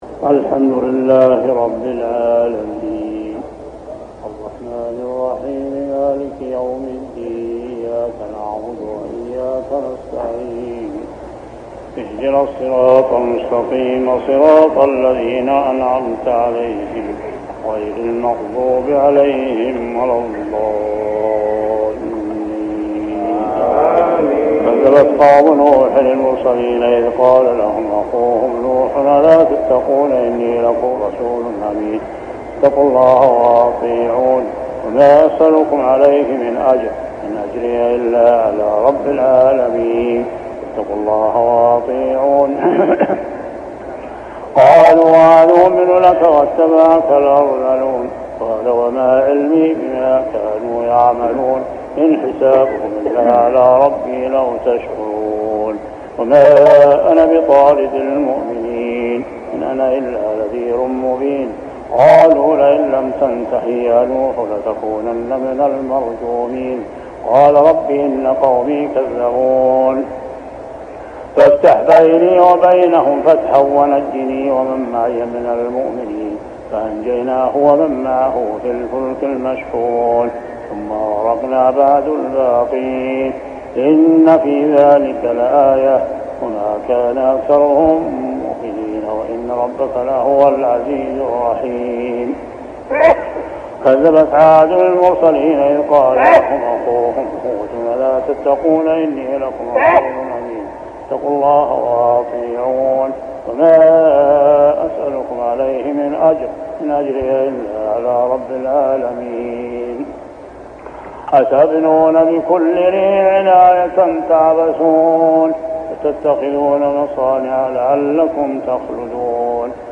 صلاة التراويح عام 1403هـ من سورتي الشعراء 105-227 و النمل 1-53 | Tarawih Prayer surah Ash-Shuara and An-Naml > تراويح الحرم المكي عام 1403 🕋 > التراويح - تلاوات الحرمين